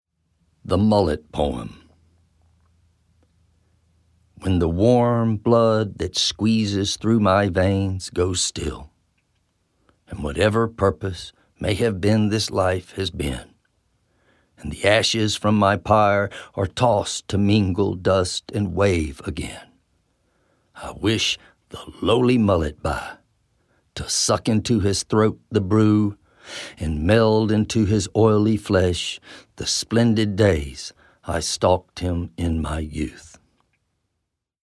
Spoken Poems